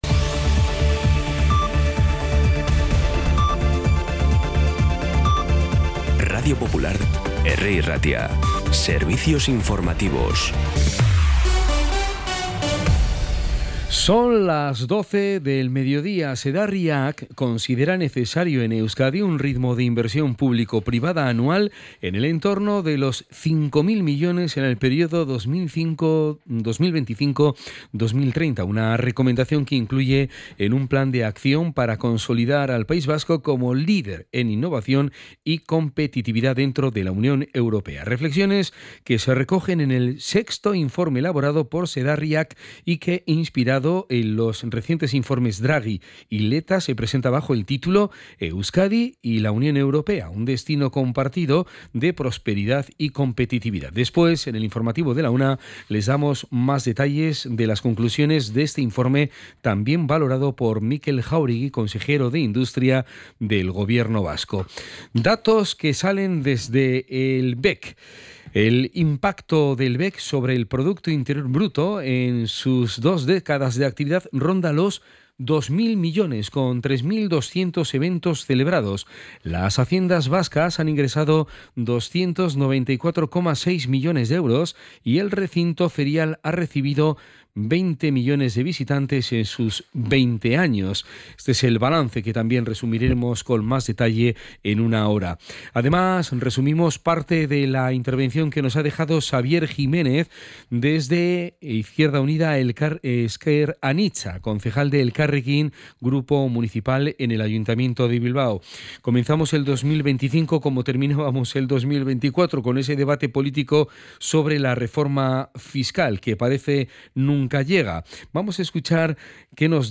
Los titulares actualizados con las voces del día.